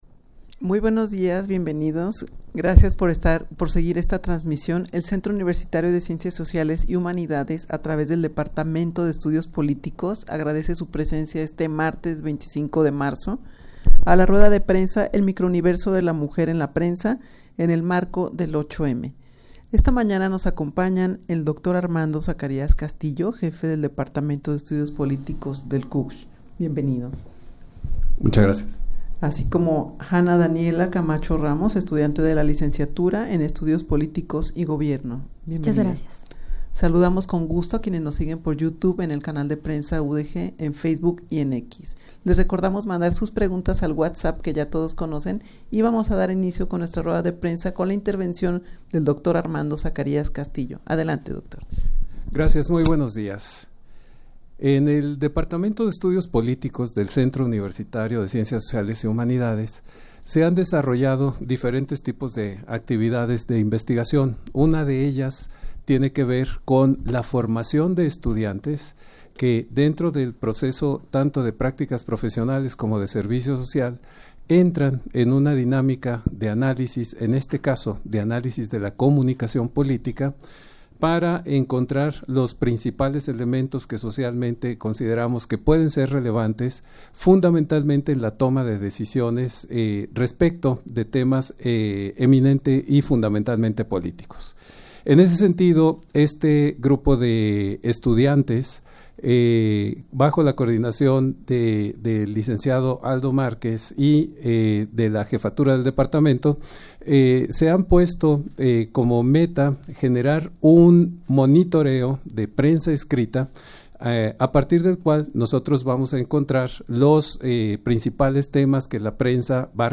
Audio de la Rueda de Prensa
rueda-de-prensa-el-microuniverso-de-la-mujer-en-la-prensa-en-el-marco-del-8m.mp3